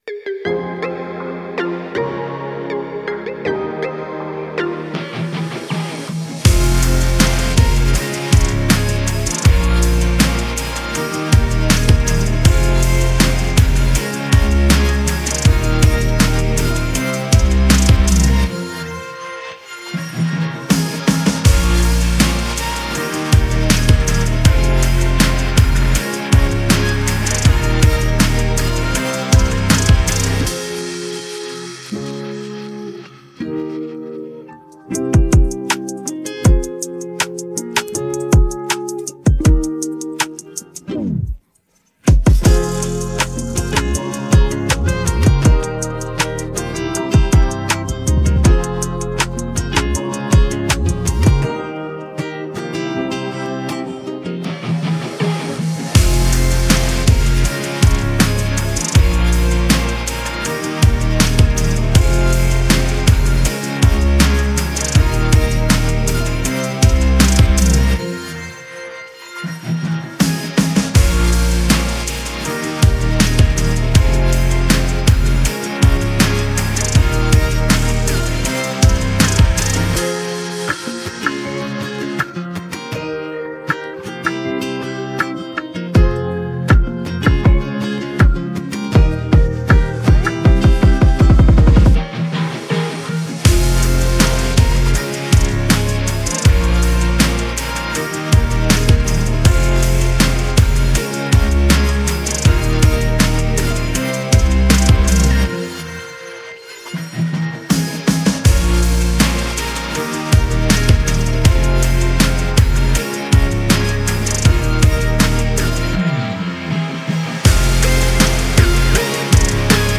Детские песни В закладки 😡 Замечание!